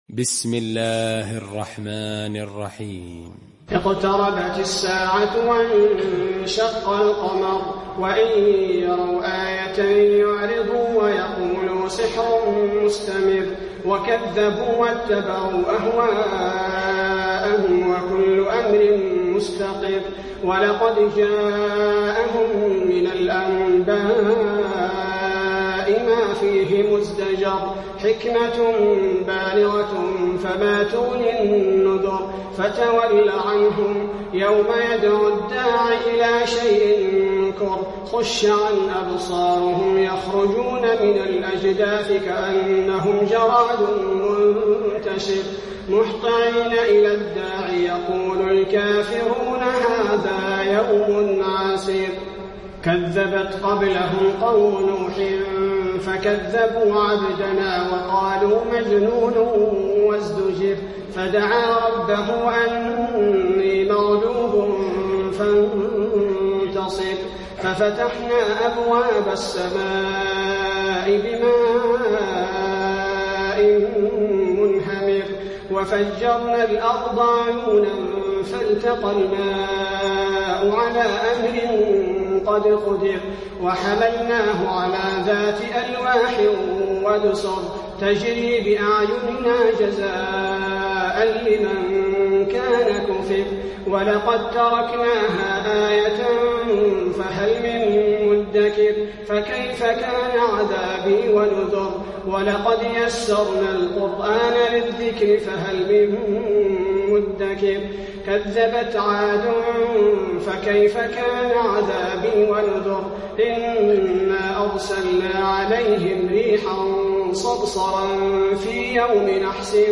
المكان: المسجد النبوي القمر